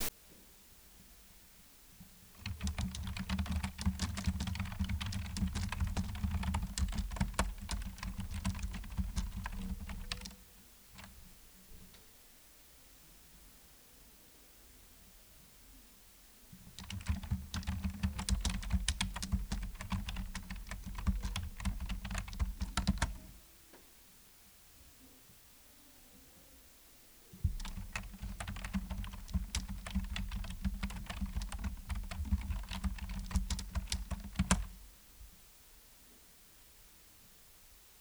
Tecleo en el ordenador
Sonido producido por el teclado de un ordenador, cuando alguien escribe un documento.